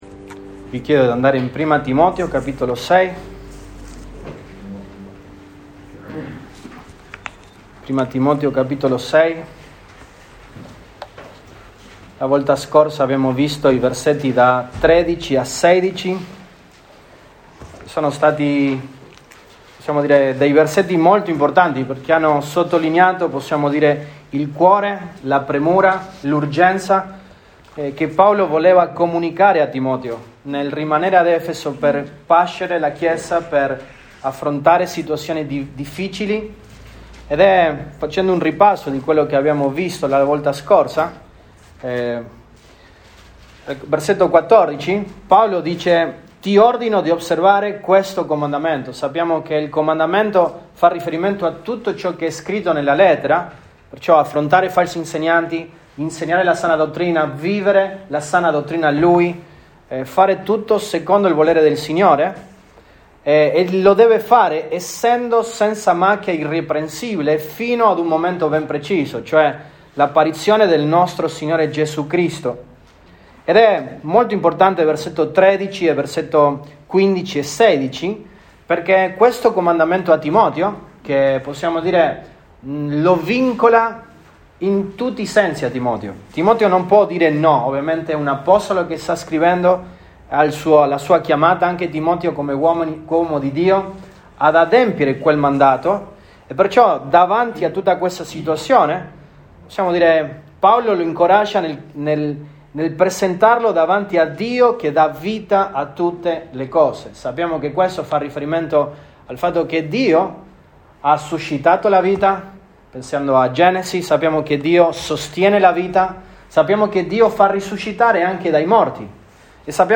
Feb 27, 2022 Il rapporto che i ricchi devono avere con le ricchezze MP3 Note Sermoni in questa serie Il rapporto che i ricchi devono avere con le ricchezze.